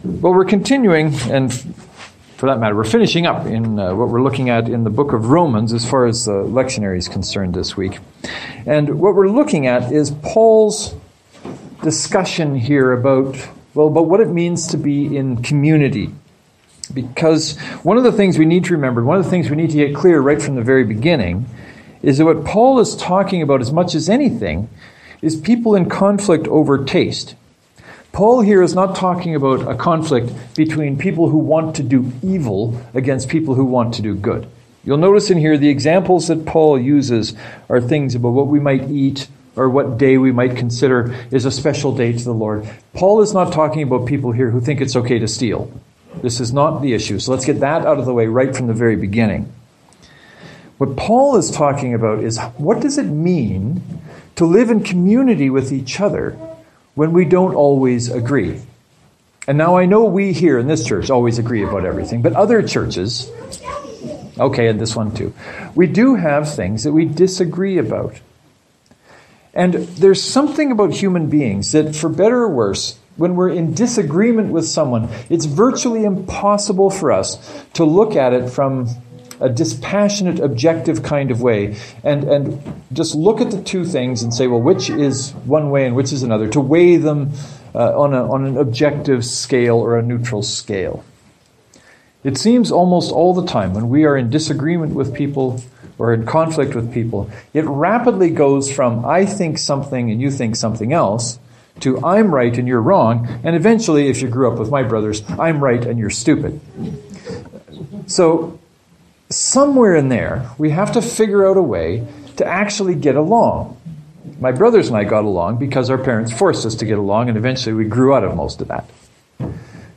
That’s what this Sunday’s sermons are after . . . let me know if I made it.